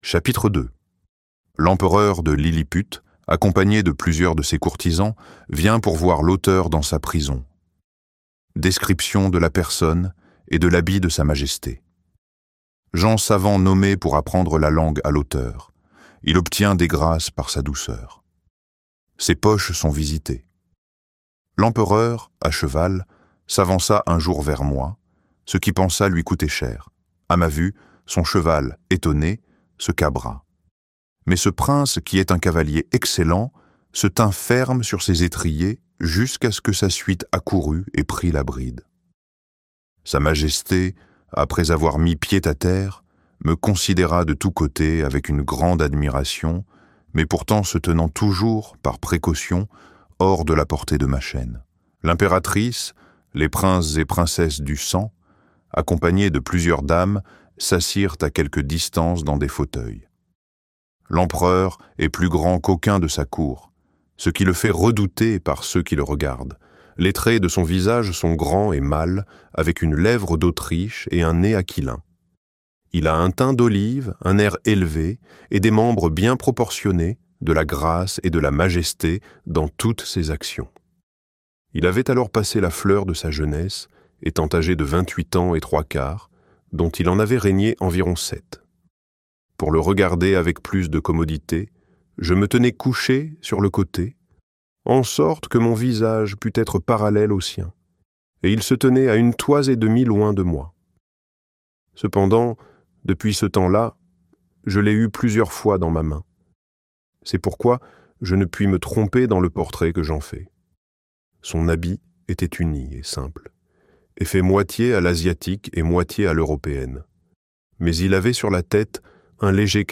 Les Voyages de Gulliver - Livre Audio
Extrait gratuit